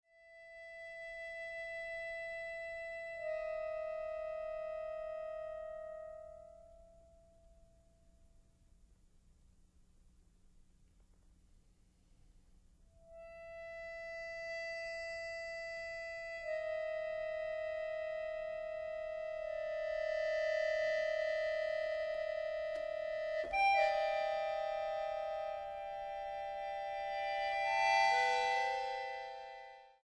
acordeonista
actrice y cantante